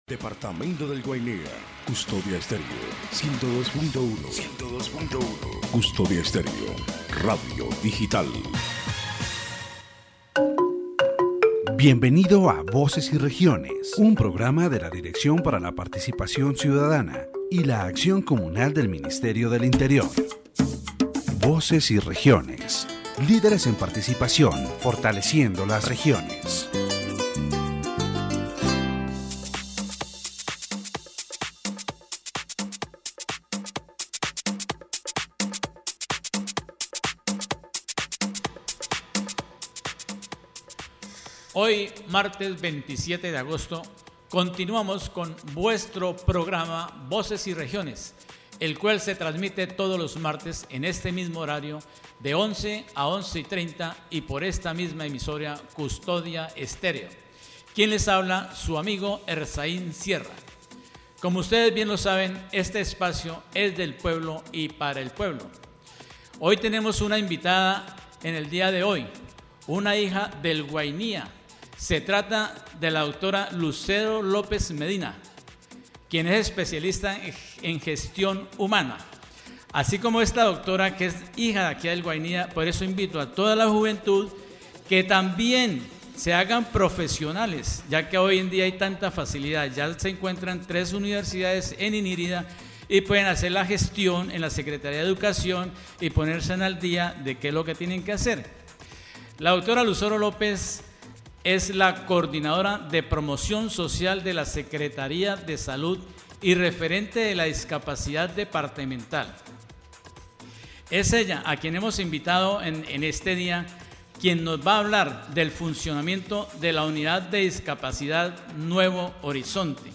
During the interview, the discussion focuses on Decree 1317 of 2017 and Law 1145 of 2007, key regulations in Colombia that promote the rights and well-being of people with disabilities.